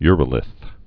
(yrə-lĭth)